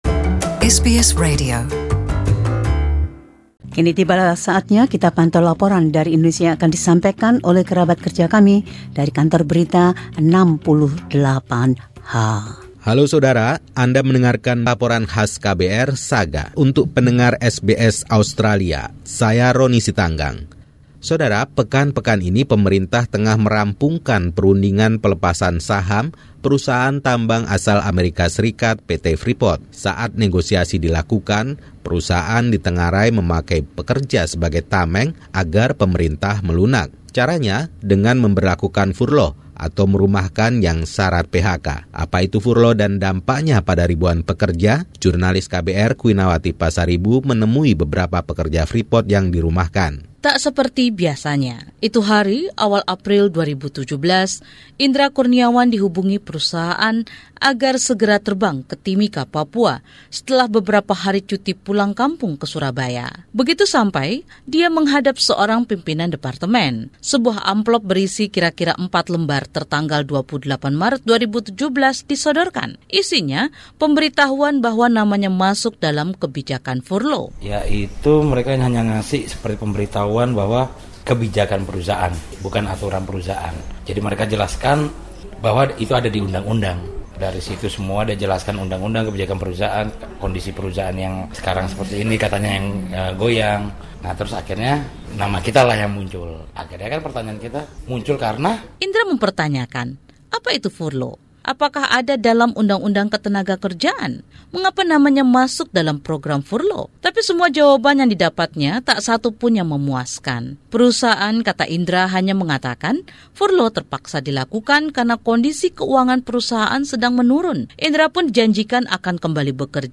A report on the impact of the Freeport company’s “Furlough” policy on workers’ lives.